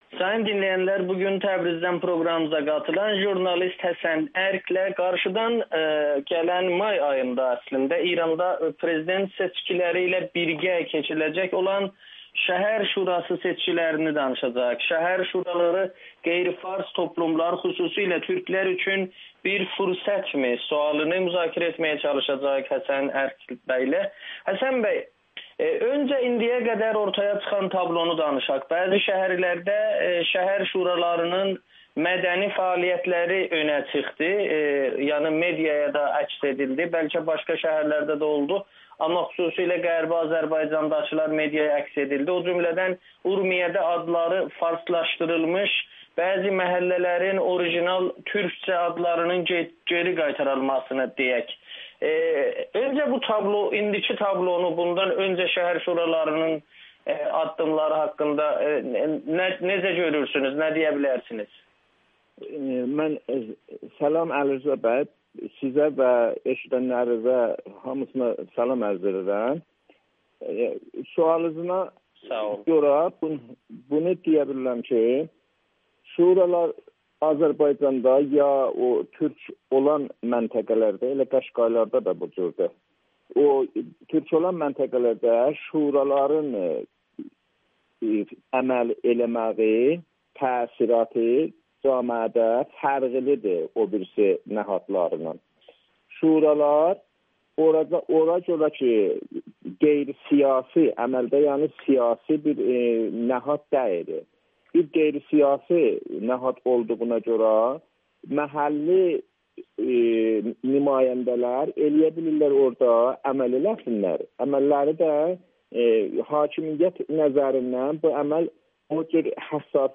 Şəhər Şuraları seçkiləri qeyri-farslar üçün fürsət ola bilər [Audio-Müsahibə]